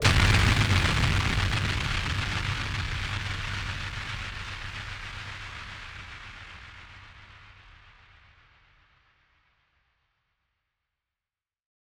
BF_DrumBombC-10.wav